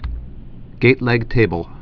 (gātlĕg)